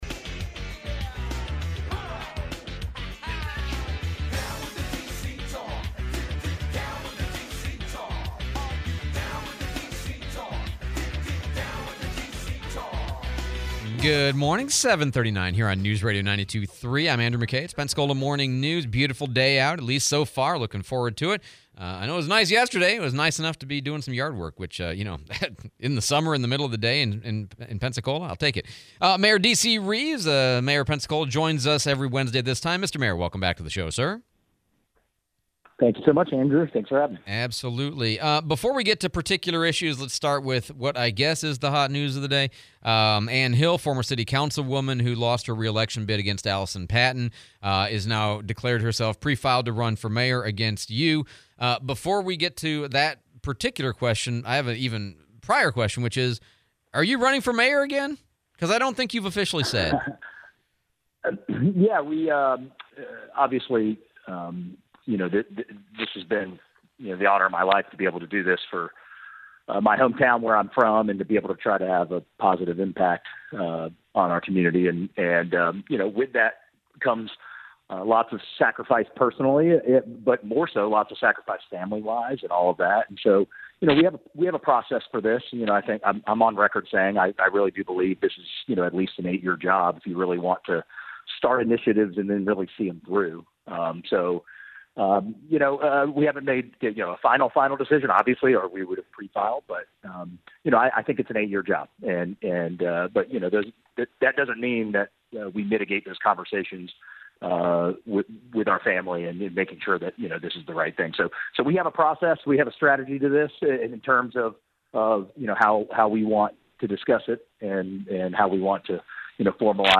09/03/25 DC Reeves interview